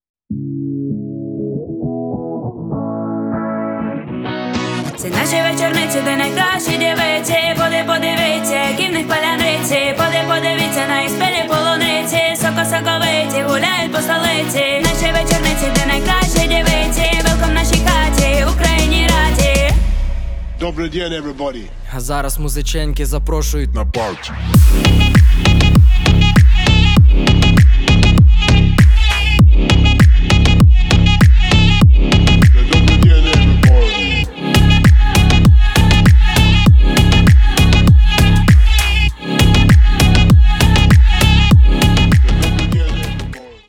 женский голос
крутые
качающие